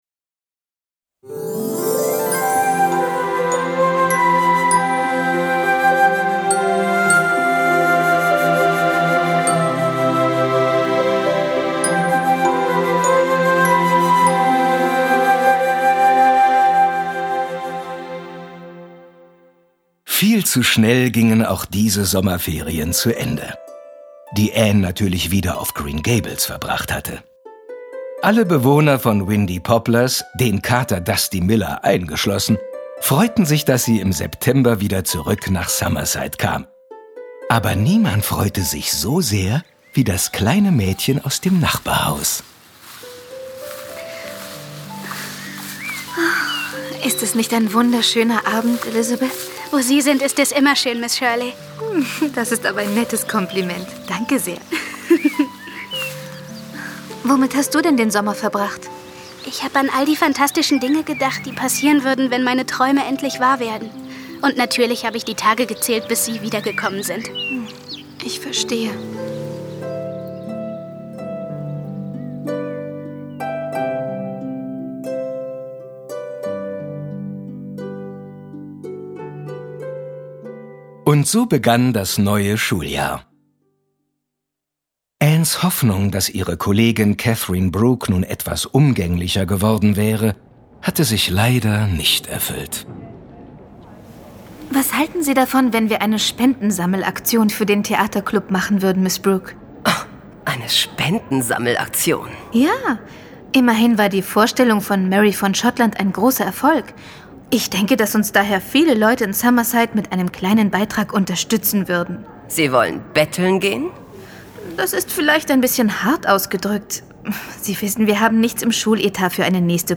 Anne in Windy Poplars - Folge 15 Das zweite Jahr in Summerside. L.M. Montgomery (Autor) Marie Bierstedt (Sprecher) Audio-CD 2009 | 8.